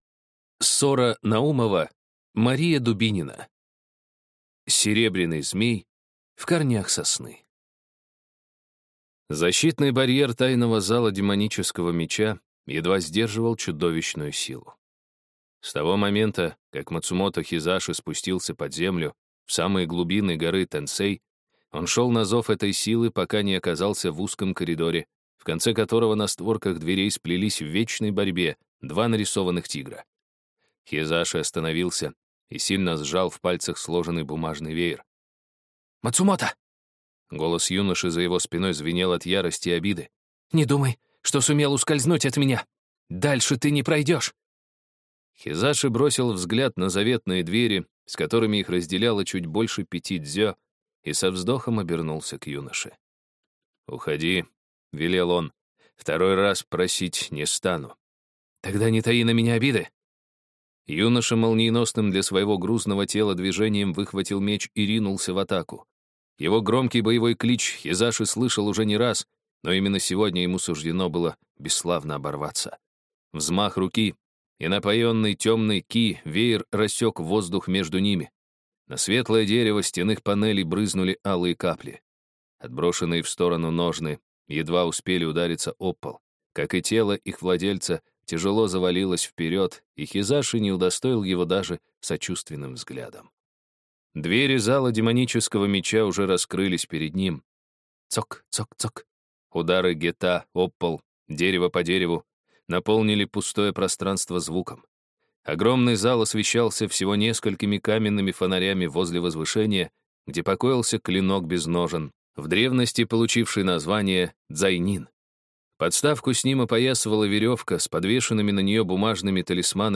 Аудиокнига Серебряный змей в корнях сосны | Библиотека аудиокниг